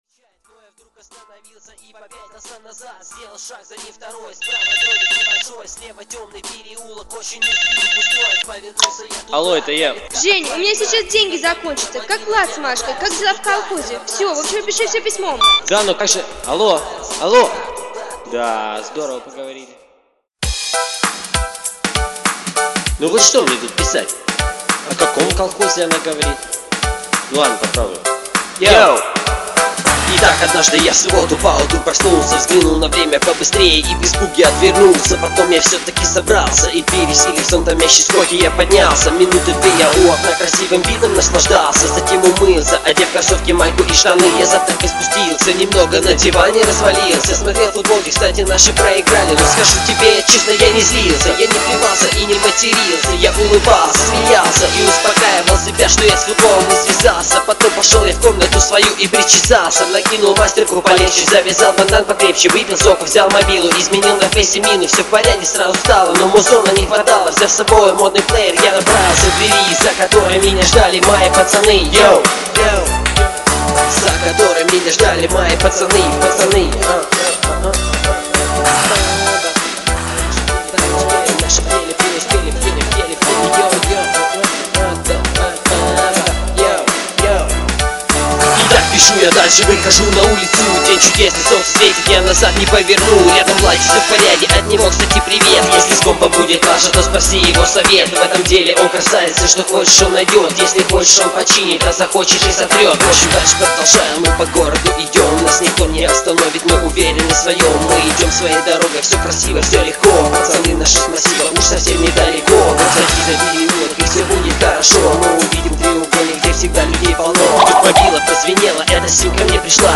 • Жанр: Рэп